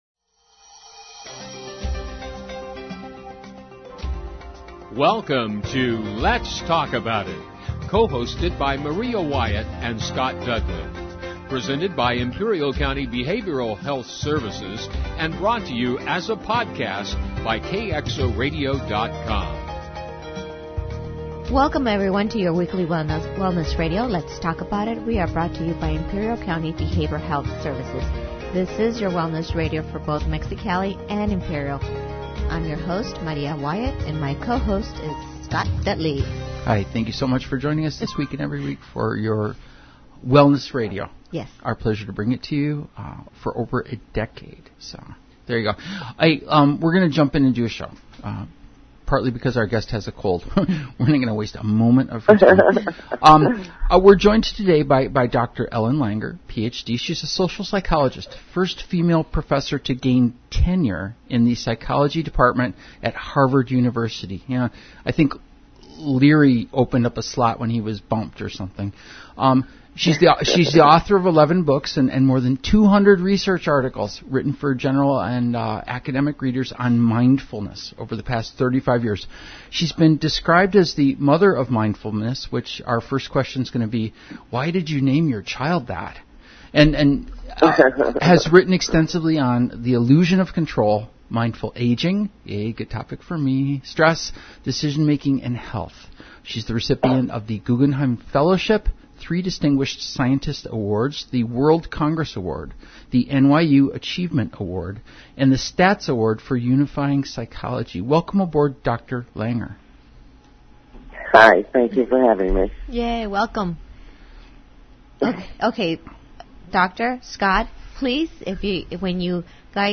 Talk Radio/Behavioral health - Results from #56